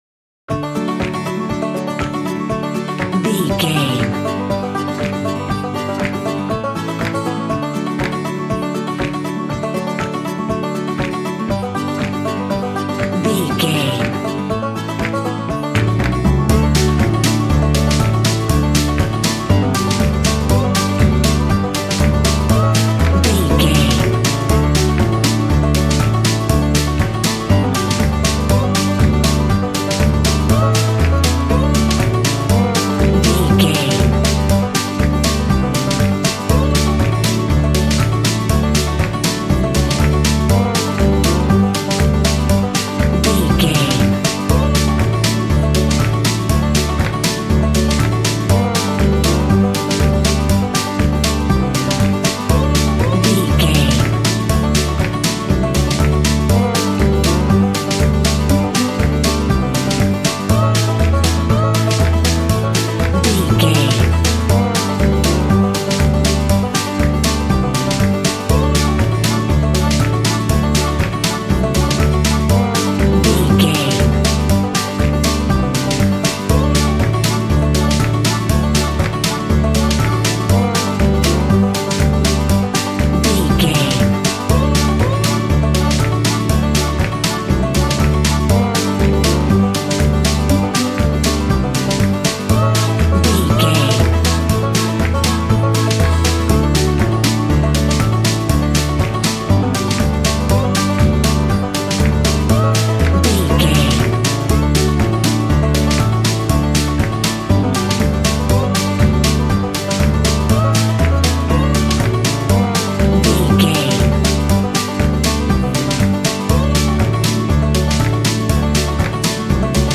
Happy and cute country music from the farmyard.
Ionian/Major
Fast
bouncy
double bass
drums
acoustic guitar